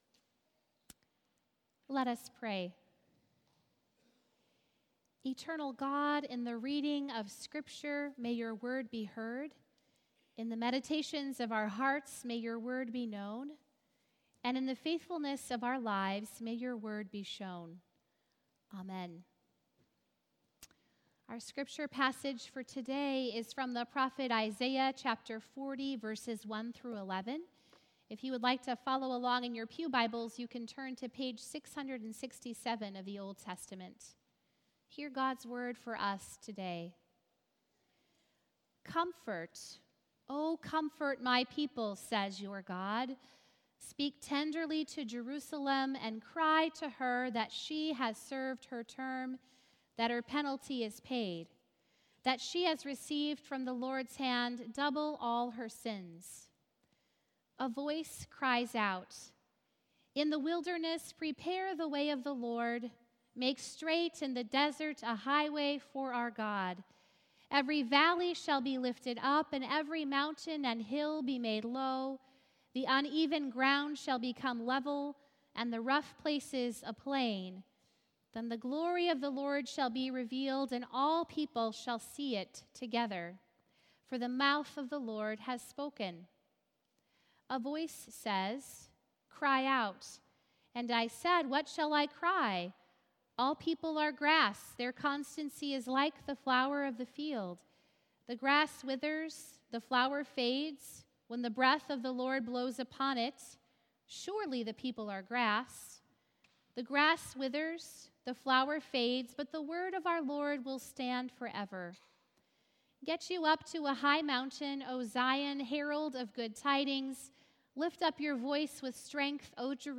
Sermons - Crossroads